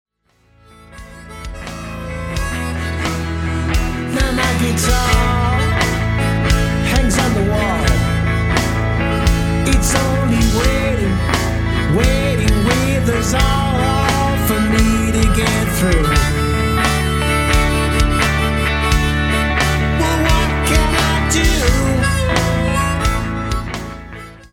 Blues tune